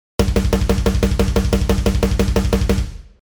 スネア表打ち
性急な印象のパターンです。
ブラストビートのサンプル2
• ブラスト（スネア表打ち）＝USデスやグラインド味の強い雰囲気